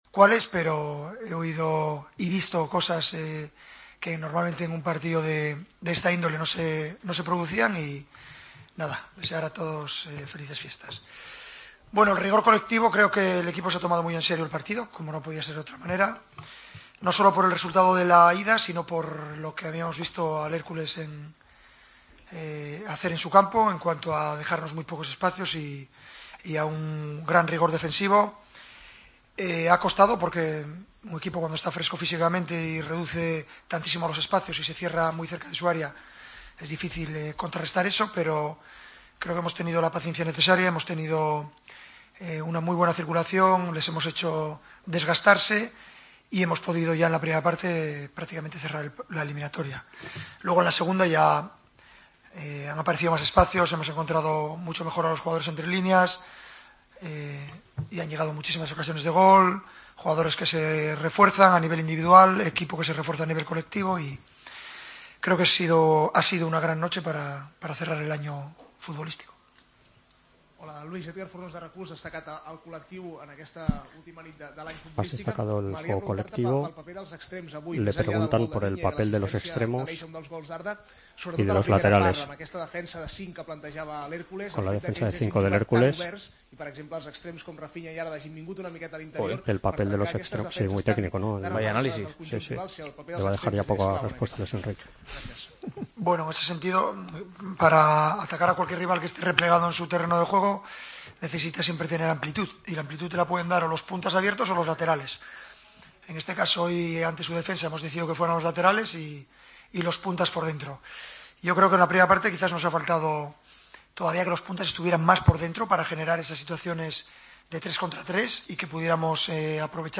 AUDIO: El entrenador del Barcelona analizó la goleada ante el Hércules en la vuelta de 1/16 de final de la Copa del Rey.